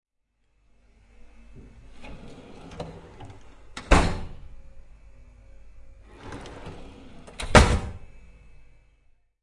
厨房抽屉里有一些餐具的噪音
描述：厨房抽屉打开和关闭。有些餐具的噪音
Tag: 厨房 打开 关闭 抽屉 餐具 噪音